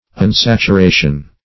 Unsaturation \Un*sat`u*ra"tion\, n.
unsaturation.mp3